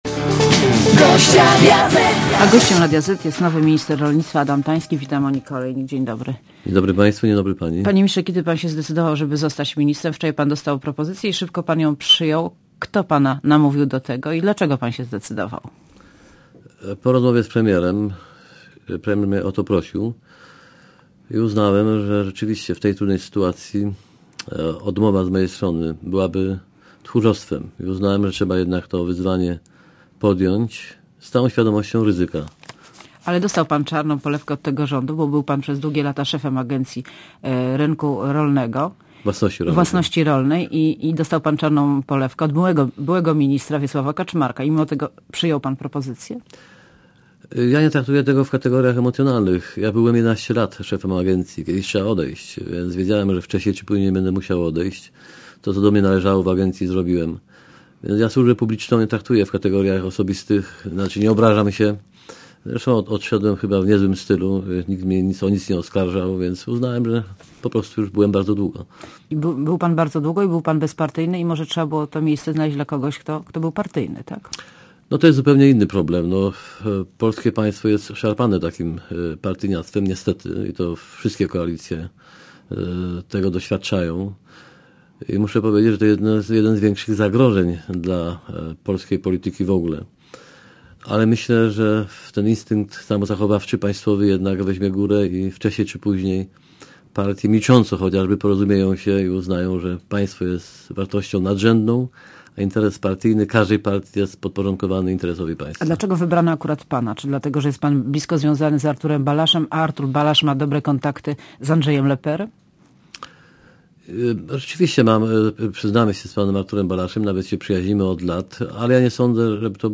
Monika Olejnik rozmawia z ministrem rolnictwa Adamem Tańskim